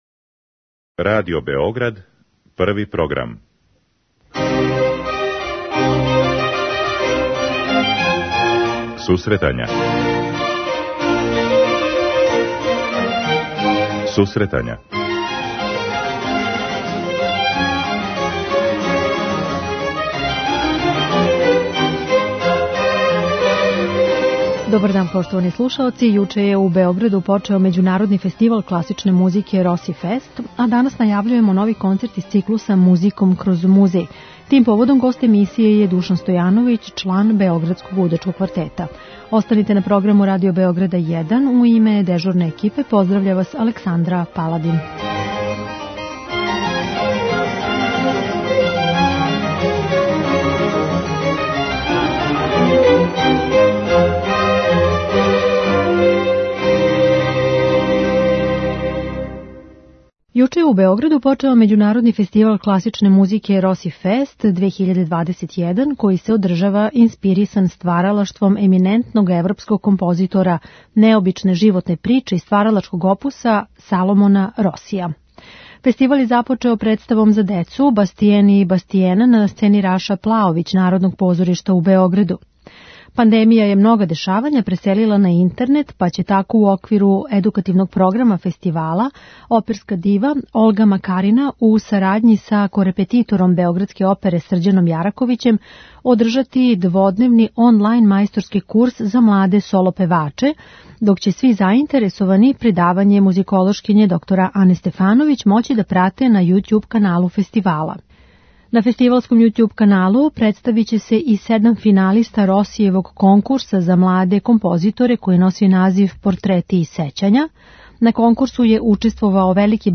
Овај концерт публика ће бити у прилици да слуша 27. јануара, у 19 часова, преко званичног Јутјуб канала и Фејсбук странице Историјског музеја Србије. Поводом овог концерта гост емисије је виолончелиста